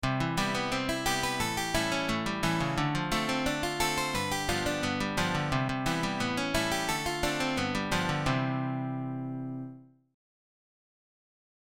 Advanced Technique Exercises > Arpeggio Exercise
Arpeggio+Exercise.mp3